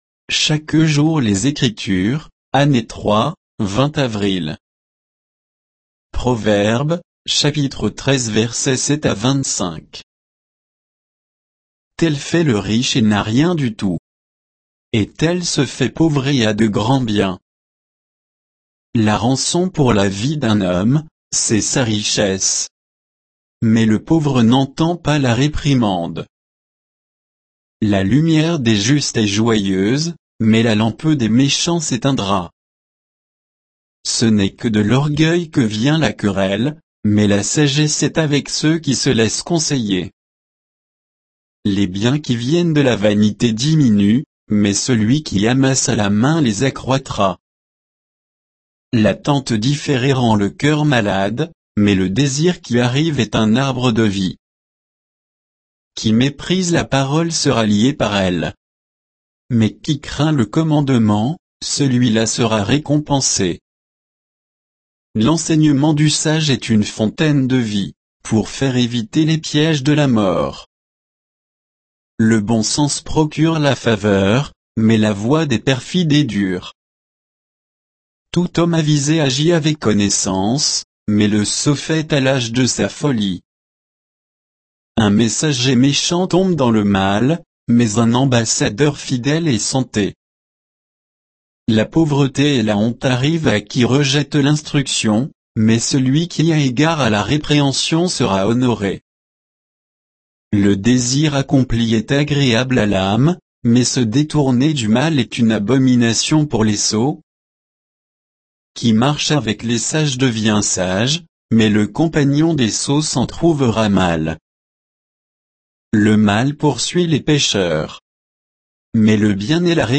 Méditation quoditienne de Chaque jour les Écritures sur Proverbes 13